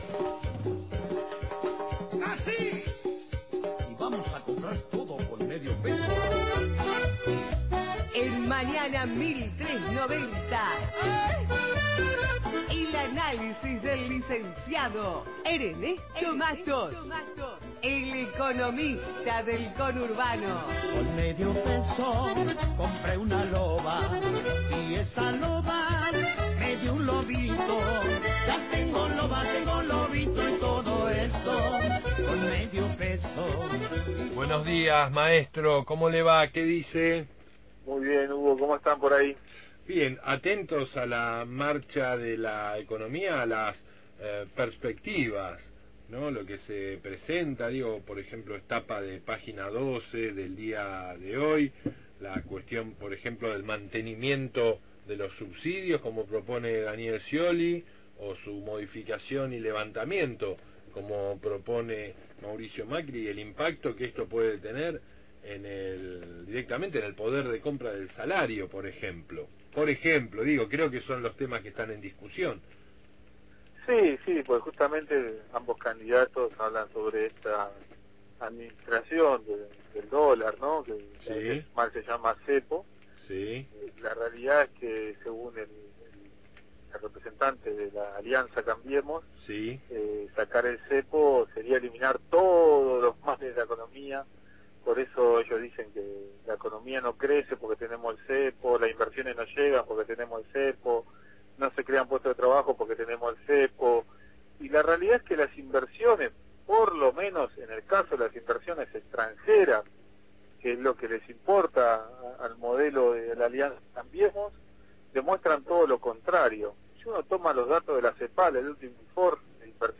columna de economía política